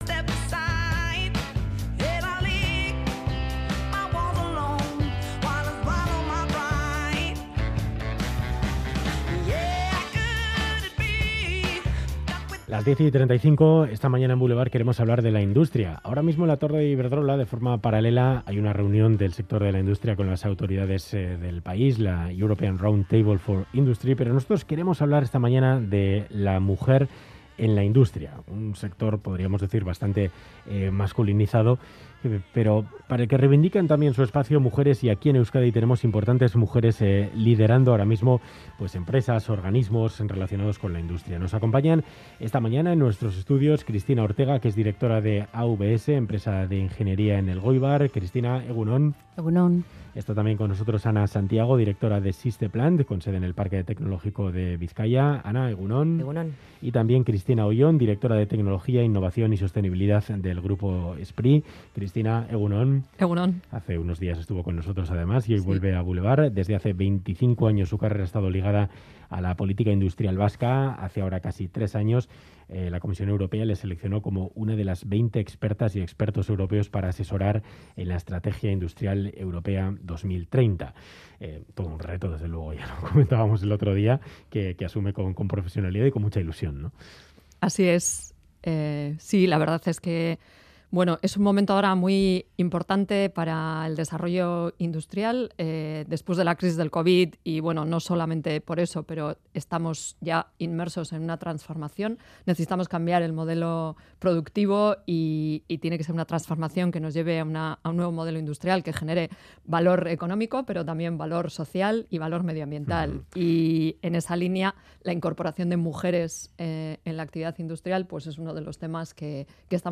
Hablamos sobre la industria vasca con tres directivas que se encuentran al frente de importantes empresas y organismos de Euskadi. En la actividad industrial las mujeres no superan el 30 %.